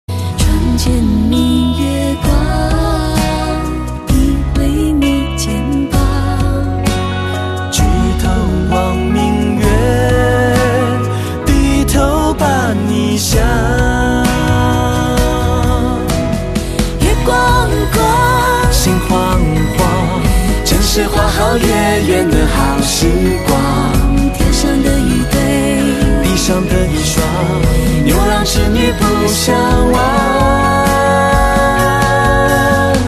华语歌曲
对唱 影视